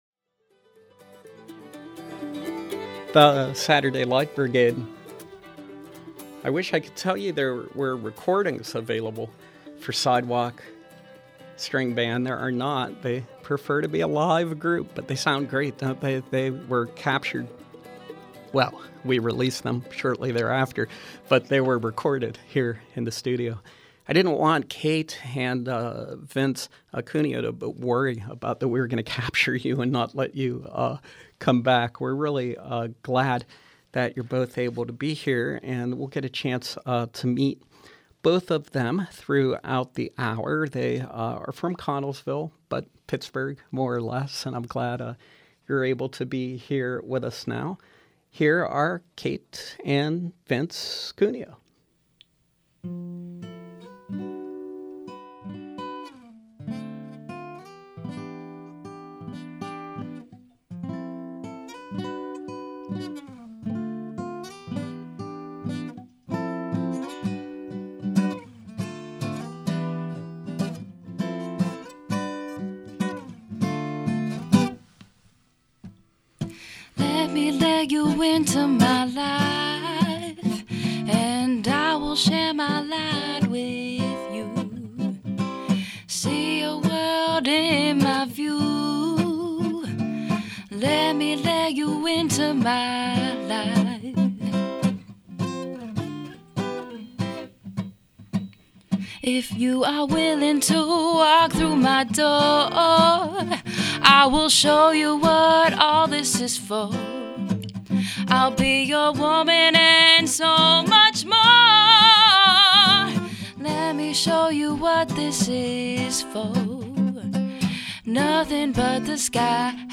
whose music combines elements of hip hop, soul and R&B